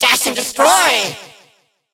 evil_mortis_atk_vo_01.ogg